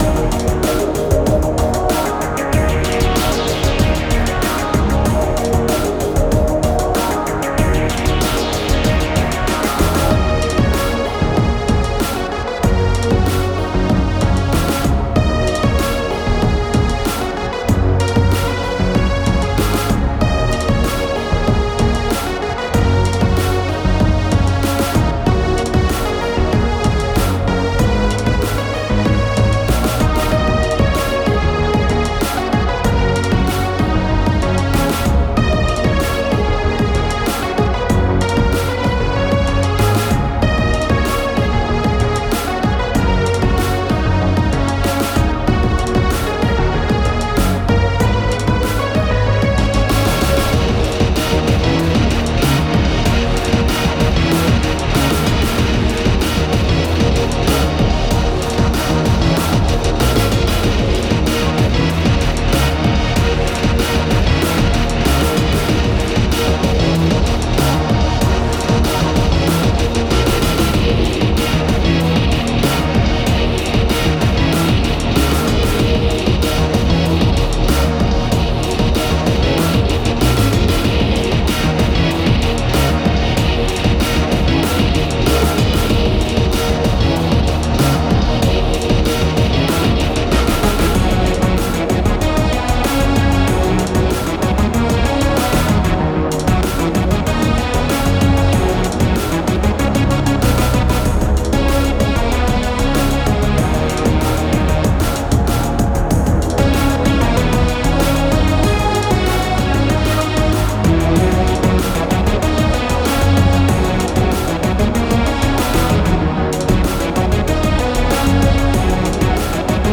Music for puzzle game.